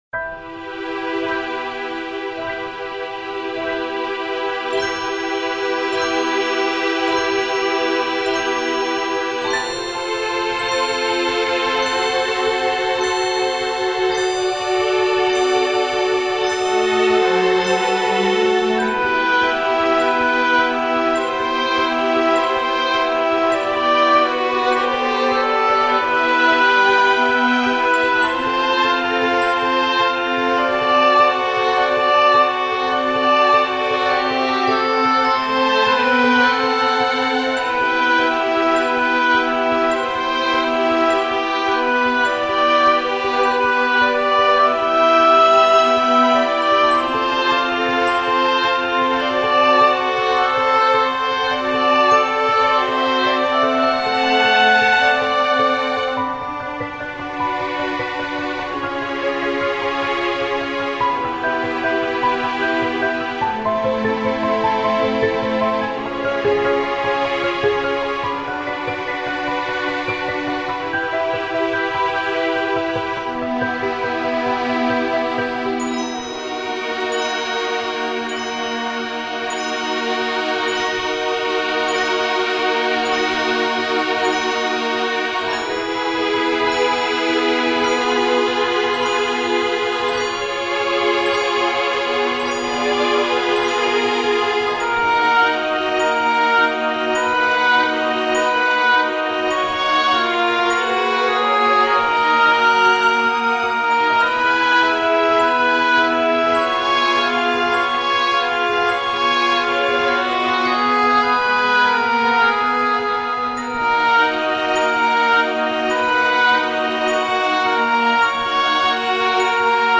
At least I've gotten marginally better at using the oboe.